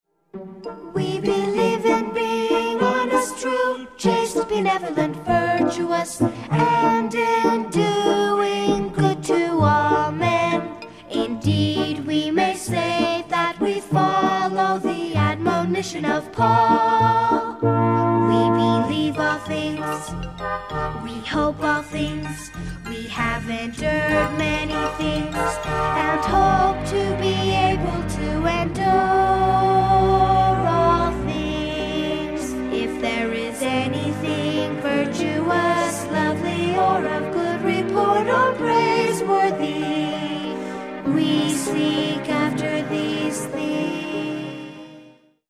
Selected Song Samples